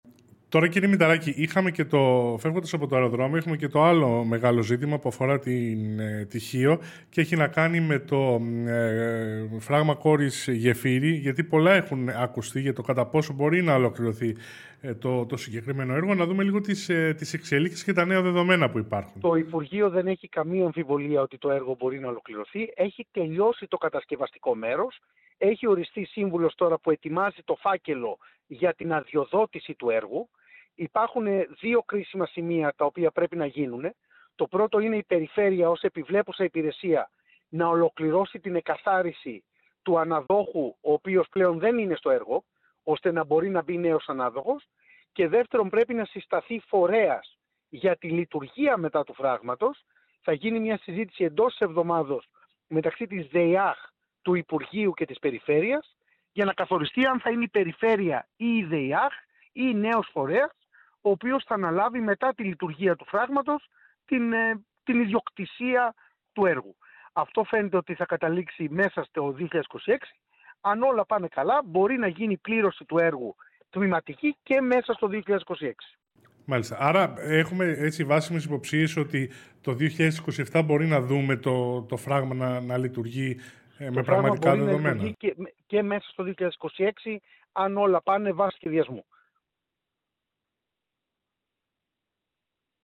Τη βεβαιότητα ότι το φράγμα Κόρης Γεφύρι μπορεί να λειτουργήσει εντός του 2026, υπό την προϋπόθεση ότι θα προχωρήσουν κρίσιμες διοικητικές διαδικασίες, εξέφρασε ο Βουλευτής Χίου της Νέας Δημοκρατίας Νότης Μηταράκης, σε τηλεφωνική του δήλωση στον «Πολίτη», μετά την ολοκλήρωση της σύσκεψης στο Υπουργείο Υποδομών το μεσημέρι της Δευτέρας.
Μηταράκης τηλεφωνική για Κόρης Γεφύρι.mp3